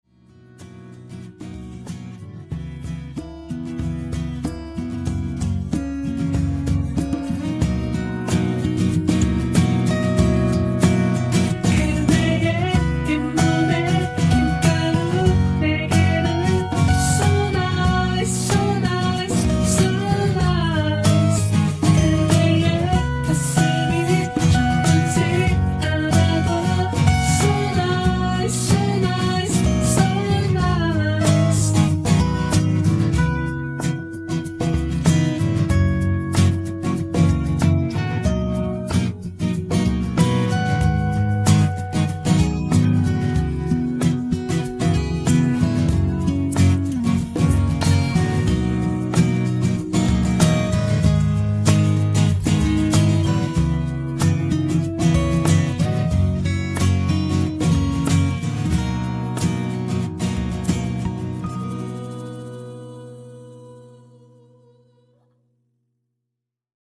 음정 원키 3:29
장르 가요 구분 Voice MR